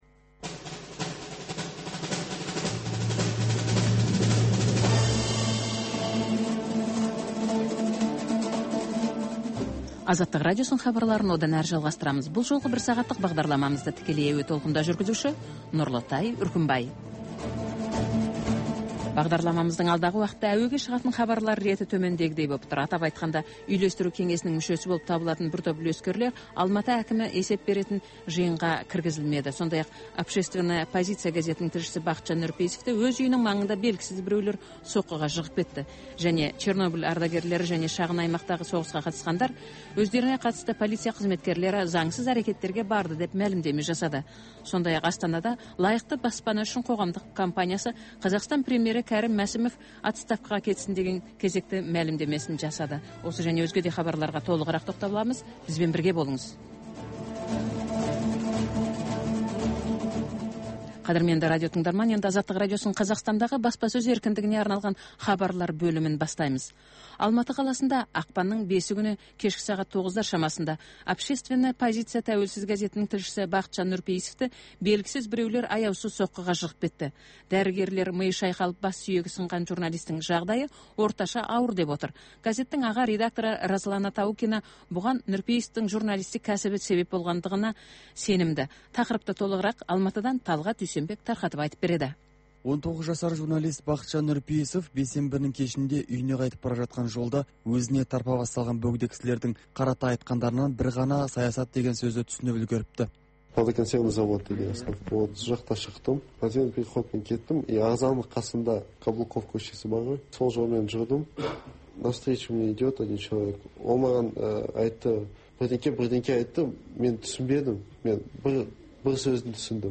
Бүгінгі күннің өзекті тақырыбына талқылаулар, оқиға ортасынан алынған репортаж, пікірталас, қазақстандық және халықаралық сарапшылар пікірі.